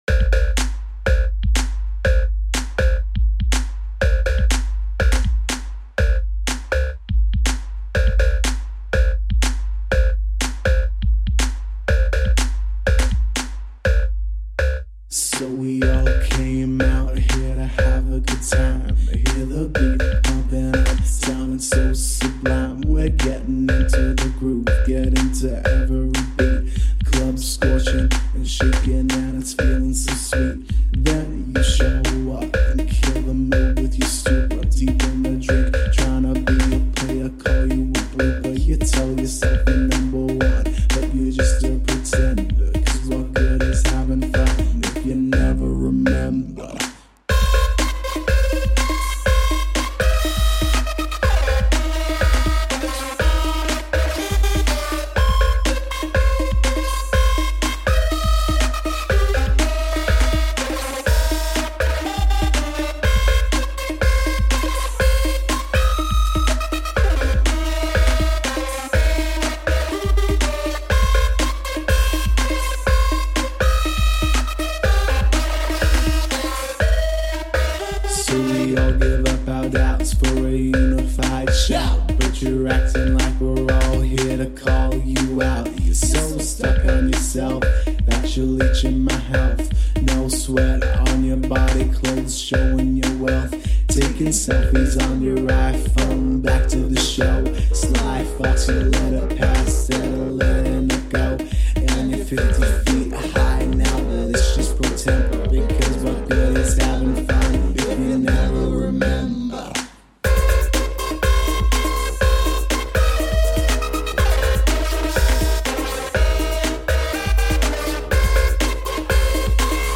electronic track